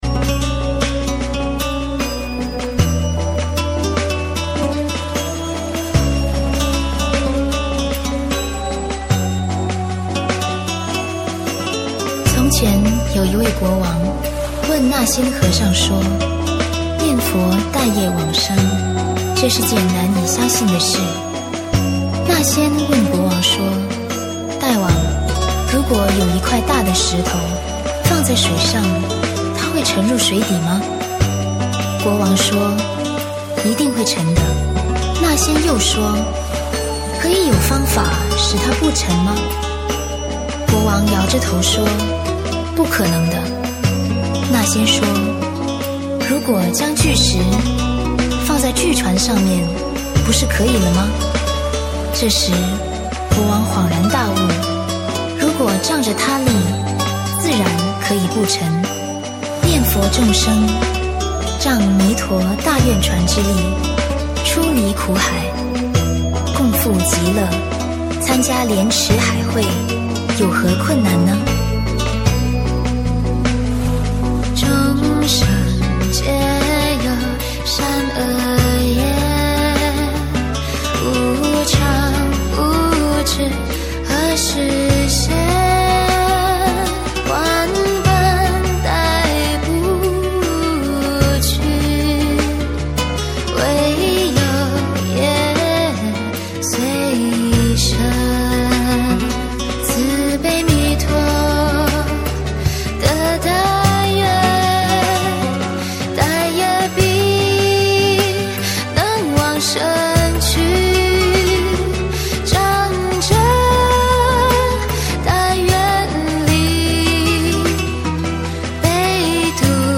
佛音 凡歌 佛教音乐 返回列表 上一篇： 观音行愿曲(童音版